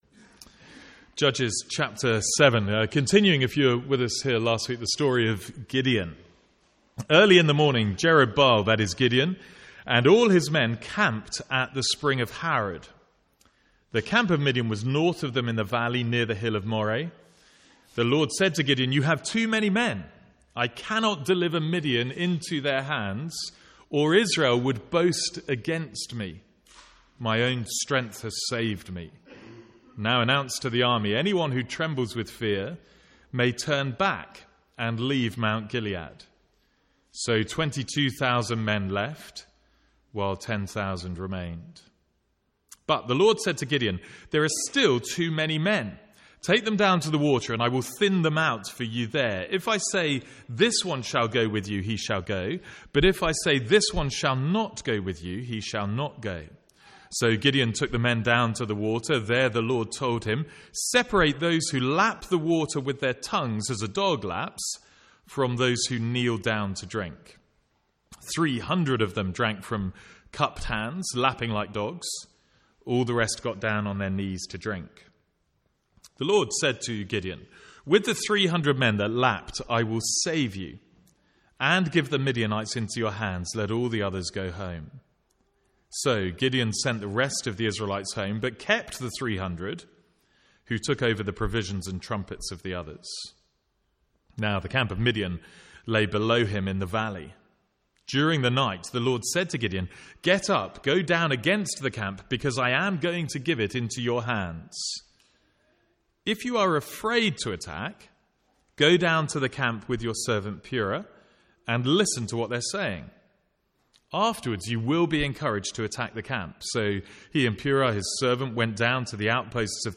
From the Sunday morning series in Judges.